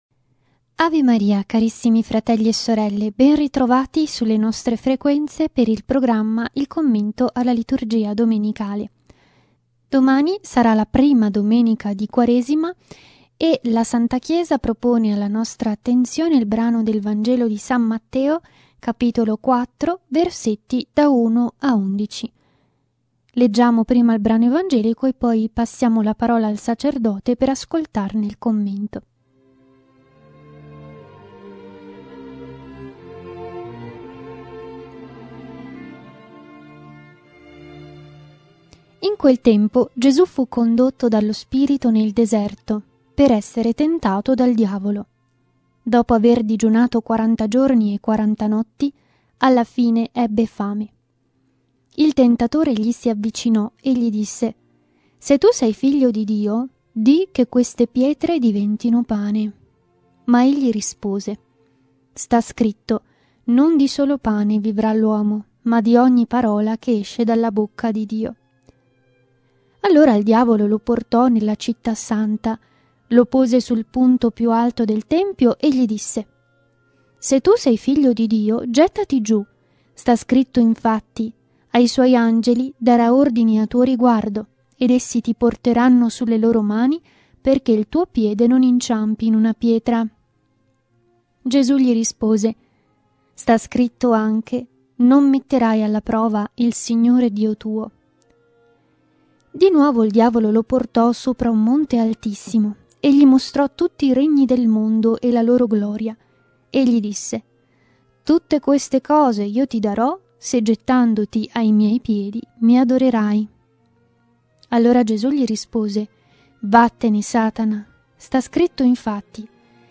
Genere: Commento al Vangelo.